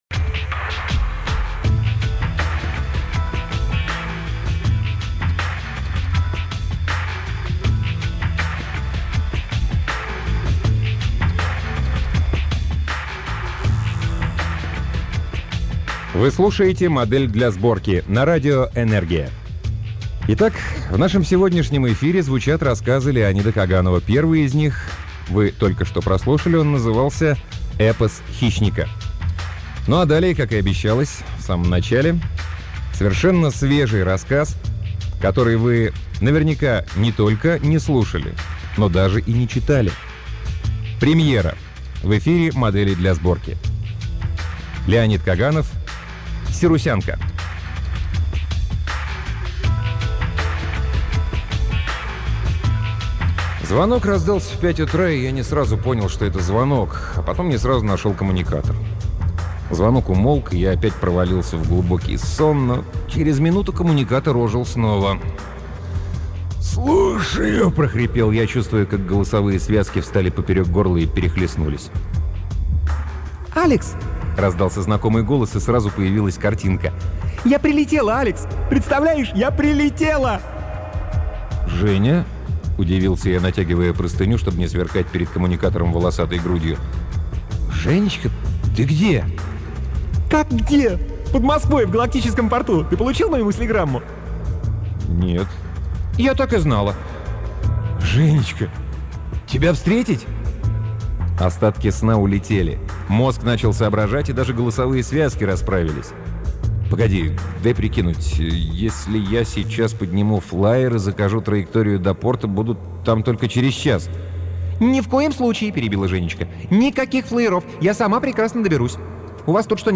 Аудиокниги передачи «Модель для сборки» онлайн